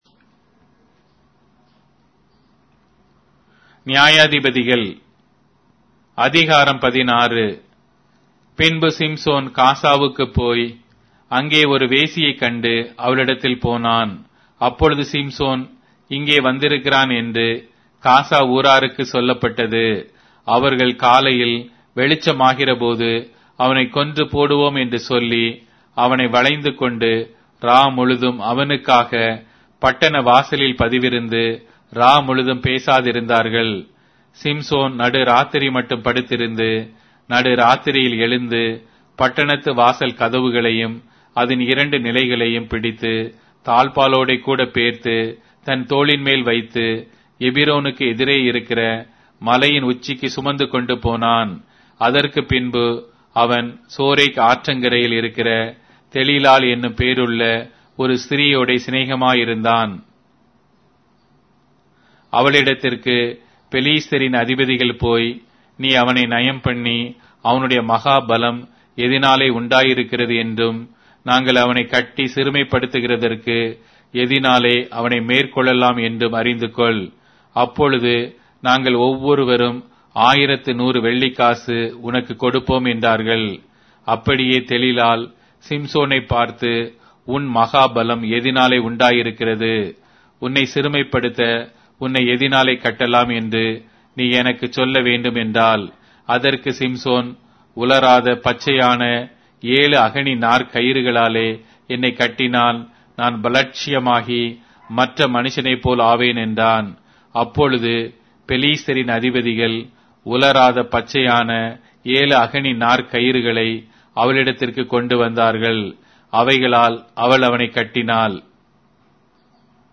Tamil Audio Bible - Judges 2 in Nlt bible version